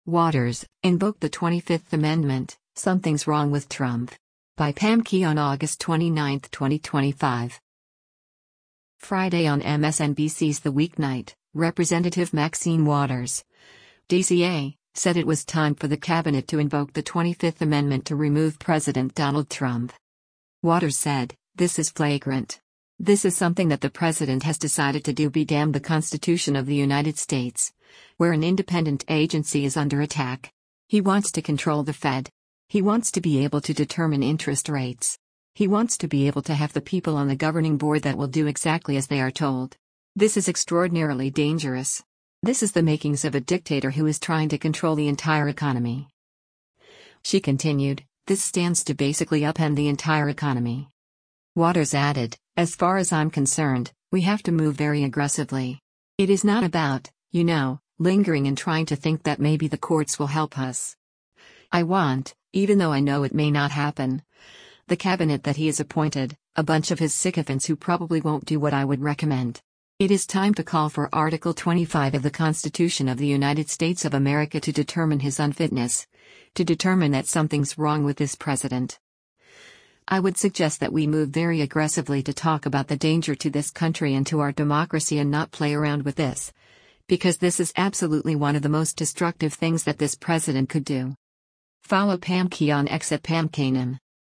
Friday on MSNBC’s “The Weeknight,” Rep. Maxine Waters (D-CA) said it was time for the cabinet to invoke the 25th Amendment to remove President Donald Trump.